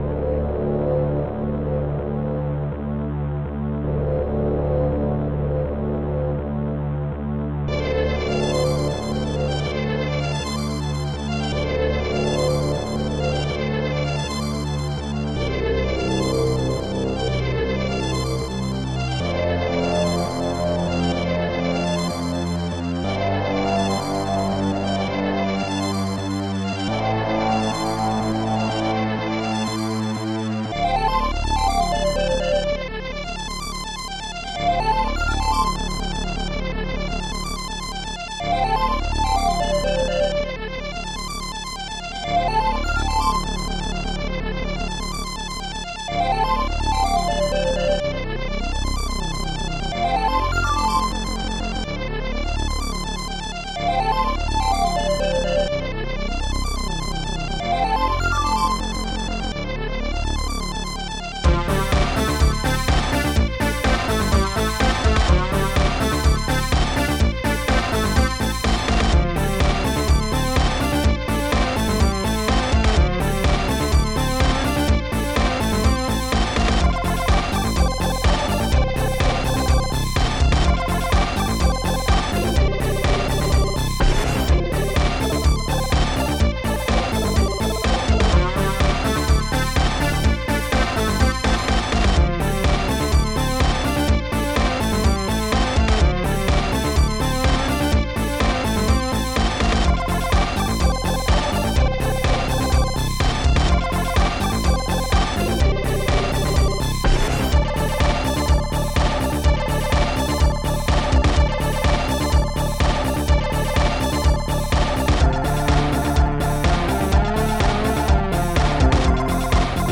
Protracker and family
st-02:bassdrum14
st-02:hihat7
st-02:steelsnare
st-03:acidbass
st-01:strings4
st-06:MiniMoog